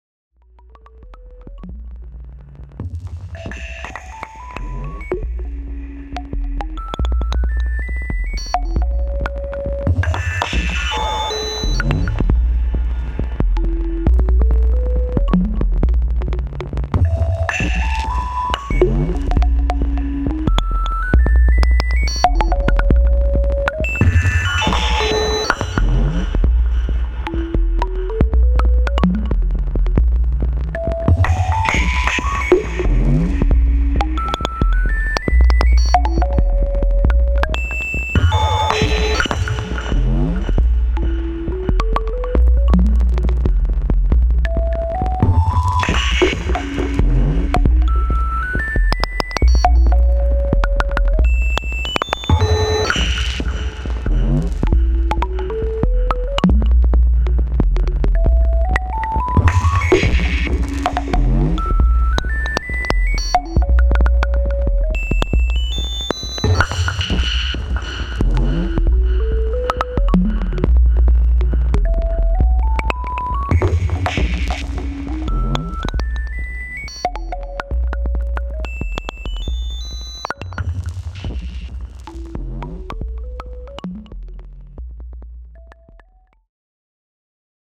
first pattern with the monomachine mk1
used 3 tracks gnd-sin, 3 tracks fx (chorus + reverb + dynamix).
No, there is a very subtle OT dark reverb + oto boum.